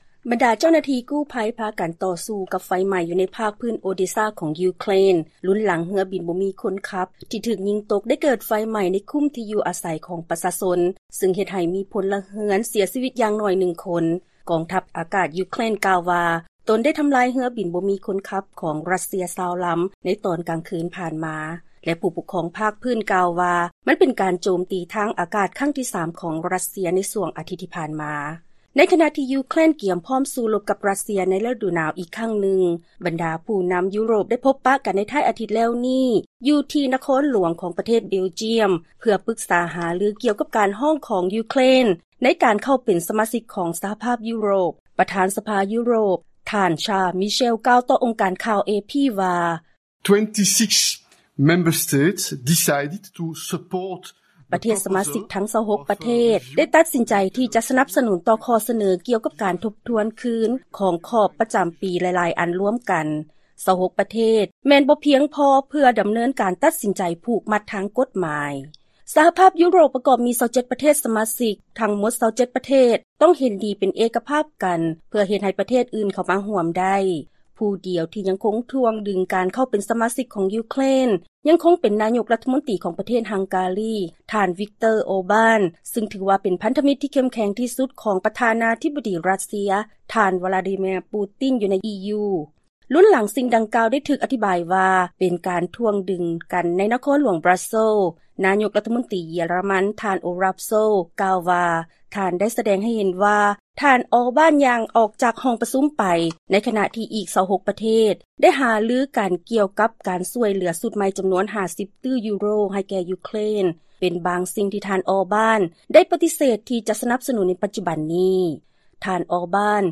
ຟັງລາຍງານກ່ຽວກັບ ການຂໍເຂົ້າເປັນສະມາຊິກສະຫະພາບ EU ຂອງ ຢູເຄຣນ